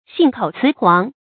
xìn kǒu cí huáng
信口雌黄发音
成语正音雌，不能读作“chí”。